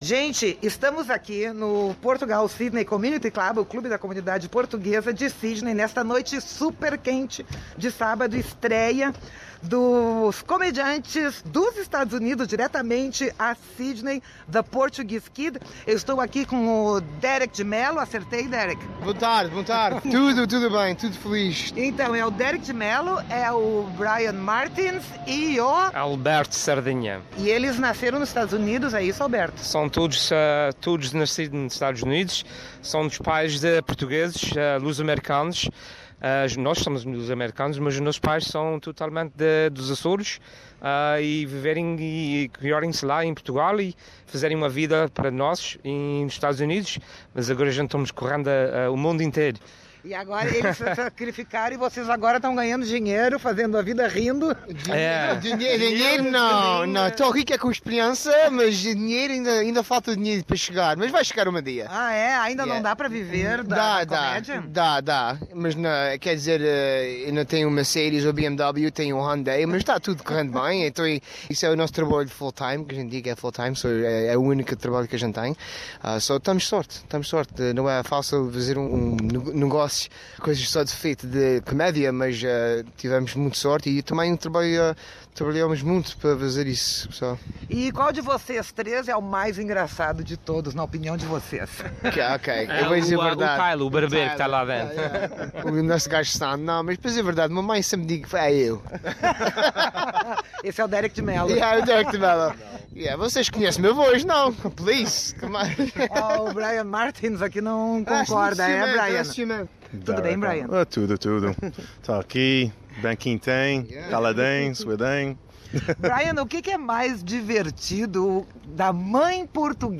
Os famosos comediantes, dos Estados Unidos, estiveram na Austrália e em entrevista ao Programa Português da Rádio SBS contaram como se conheceram, desde meninos, na igreja, e de onde tiram inspiração para fazer rir a toda a gente, com histórias de imigrantes.